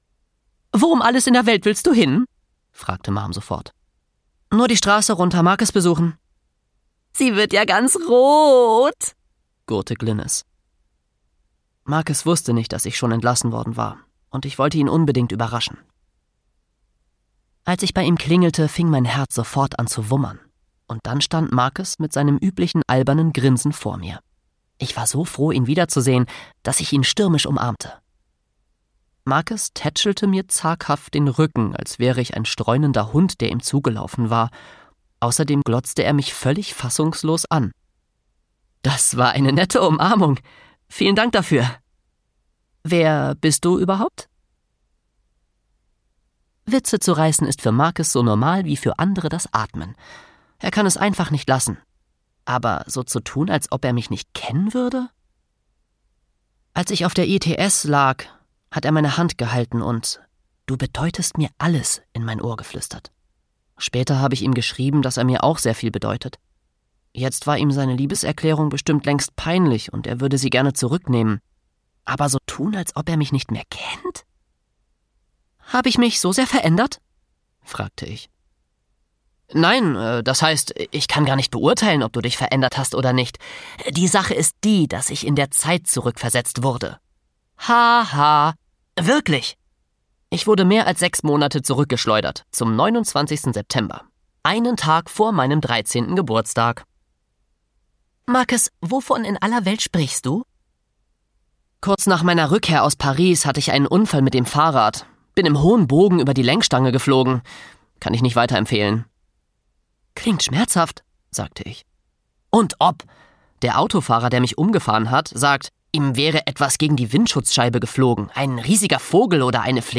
Wie man 13 wird und zum Superhelden mutiert (Wie man 13 wird 4) - Pete Johnson - Hörbuch - Legimi online